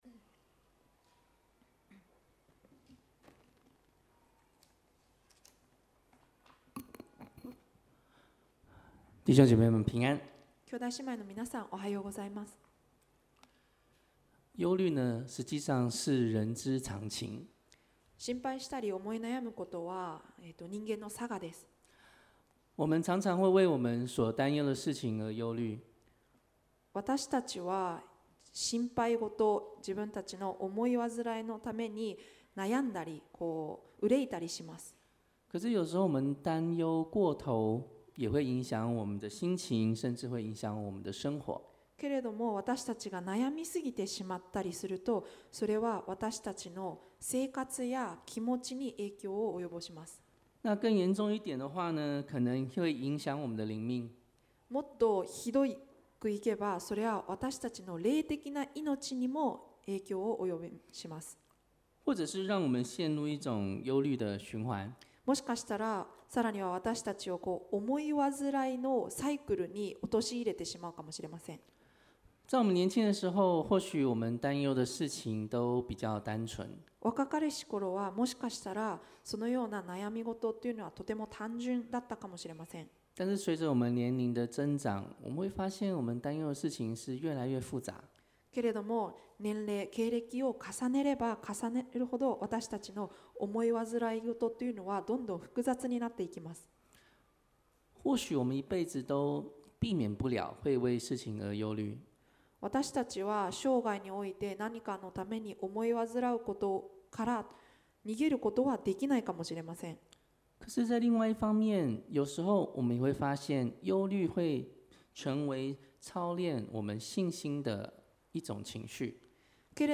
東京國際基督教会